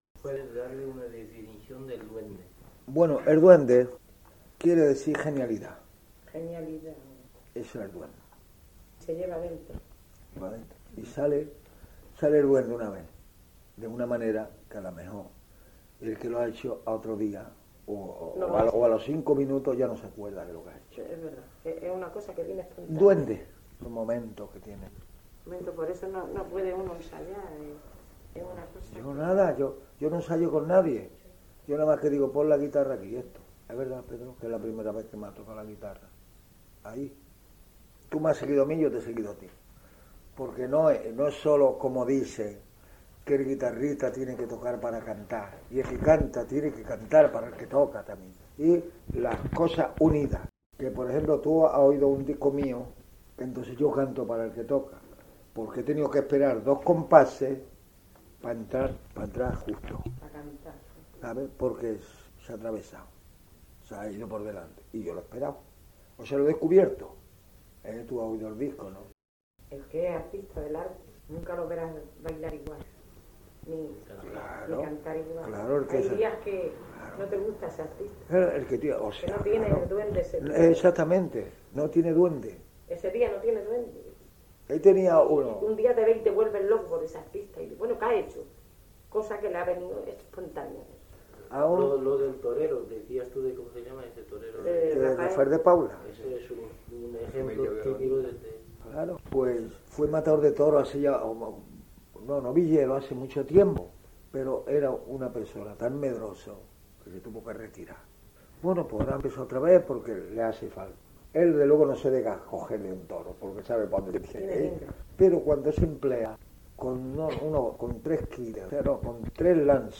NB : aussi souvent que possible, nous avons coupé les questions au montage. / lo más a menudo posible, hemos suprimido las preguntas.